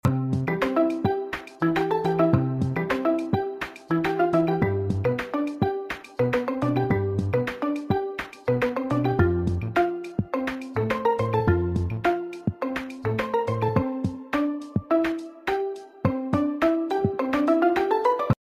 Slap Sound Effects Free Download